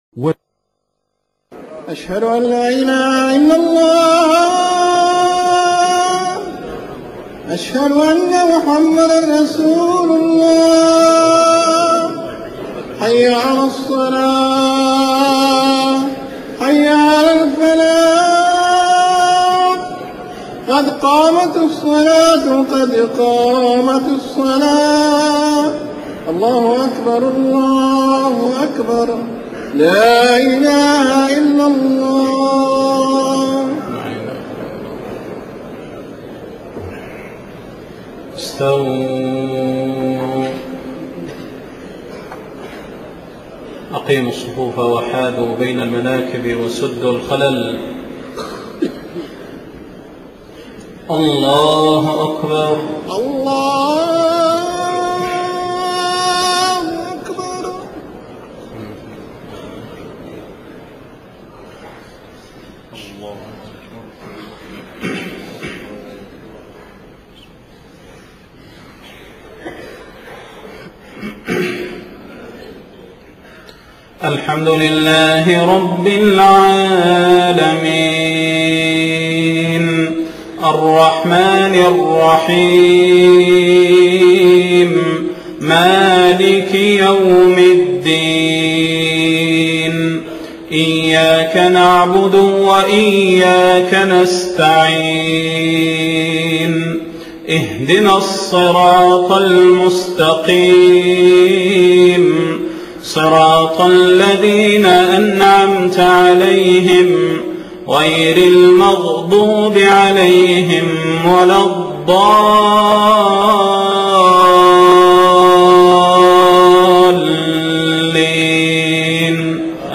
صلاة المغرب 4 محرم 1430هـ من سورة آل عمران 190-194 > 1430 🕌 > الفروض - تلاوات الحرمين